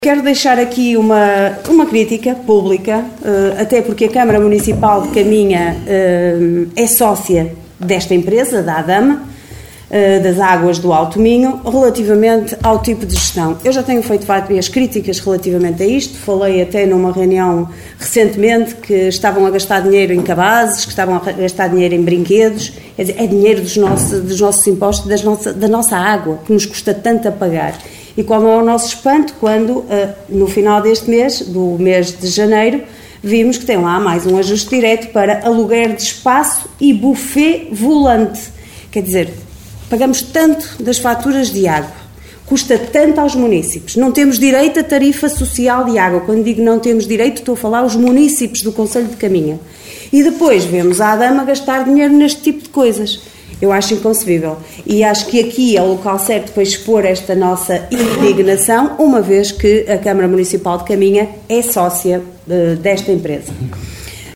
Excertos da última reunião camarária, realizada no passado dia 19 de fevereiro, no Salão Nobre dos Paços do Concelho.